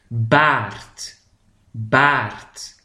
PRONCIATION